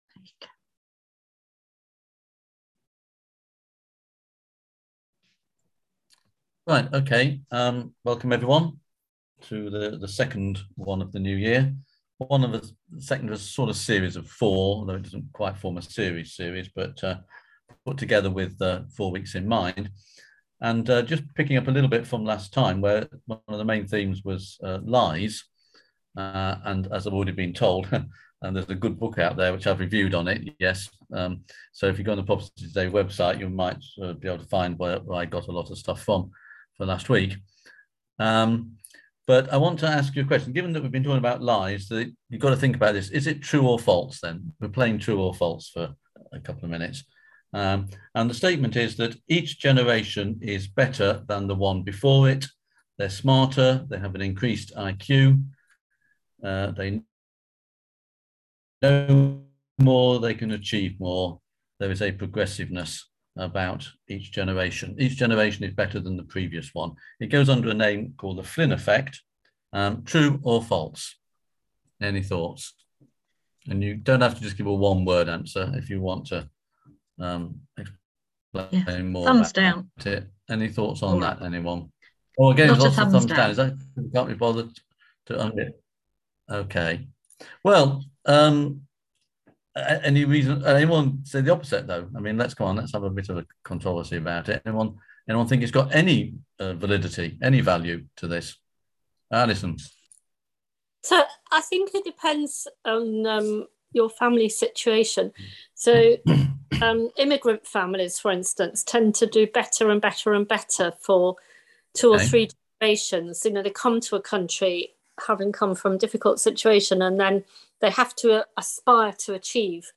On January 13th at 7pm – 8:30pm on ZOOM